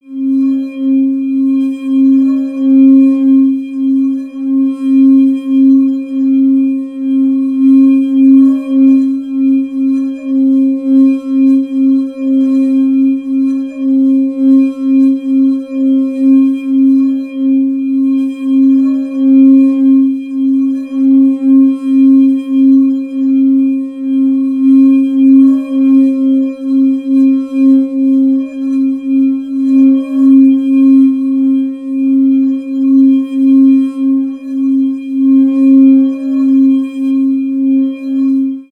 Bowl_longo.wav
bowl_longo.wav